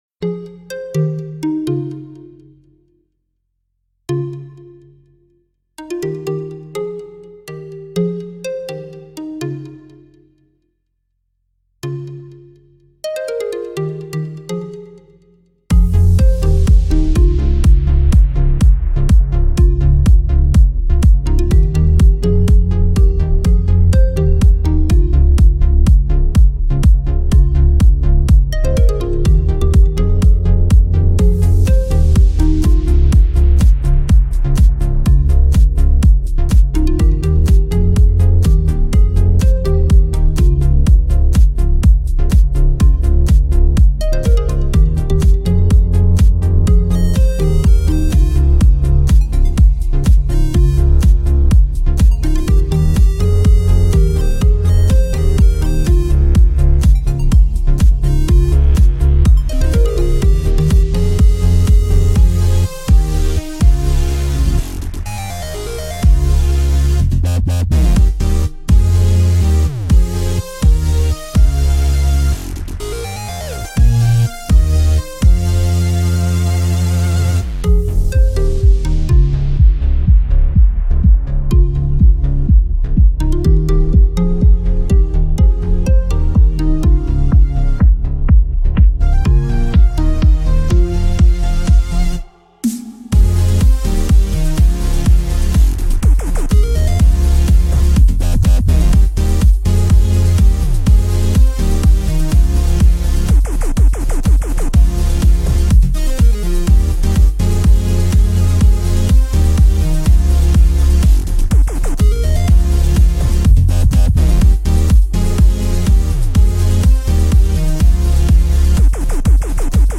EDM Electronic dance music
موسیقی بیکلام